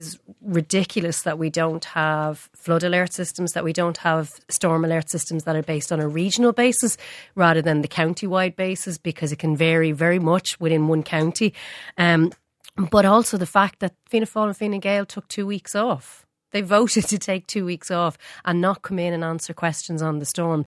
Sinn Féin MEP Lynn Boylan says the government choosing to not recall the Dáil and answers questions about the crisis was wrong: